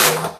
VEC3 Percussion 025.wav